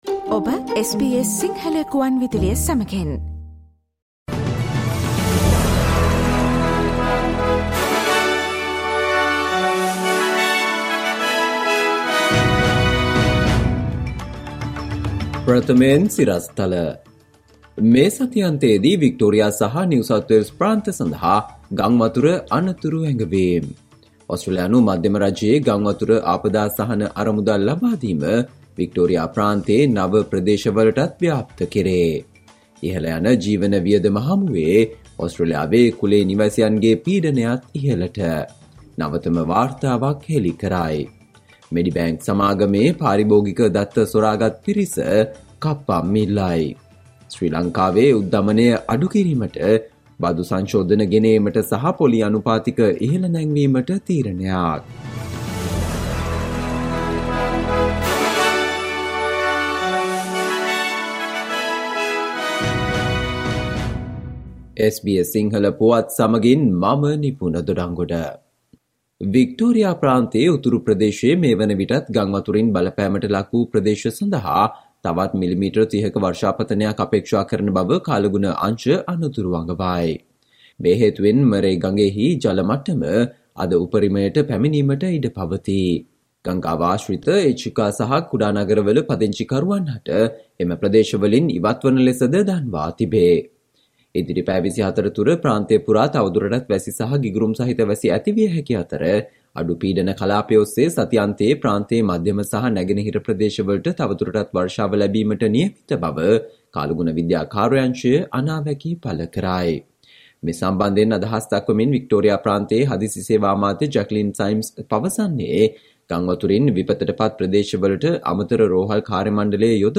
Listen to the SBS Sinhala Radio news bulletin on Friday 21 October 2022